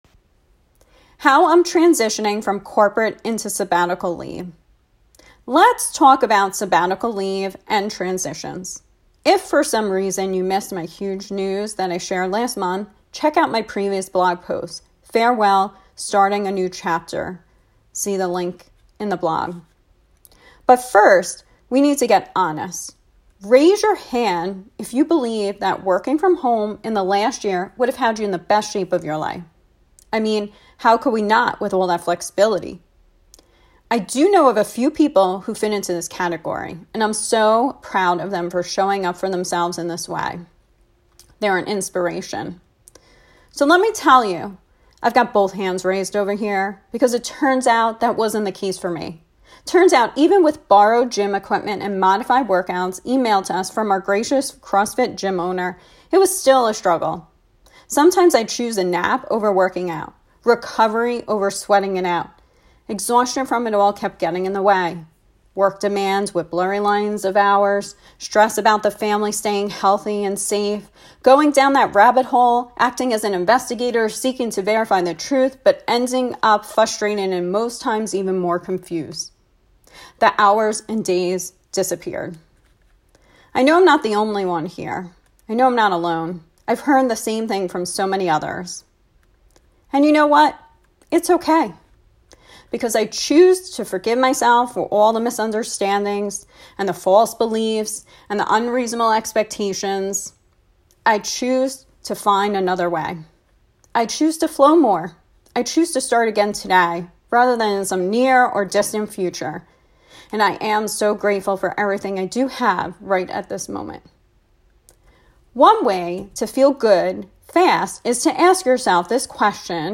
(Here’s the voice memo of this message if you prefer to listen instead.)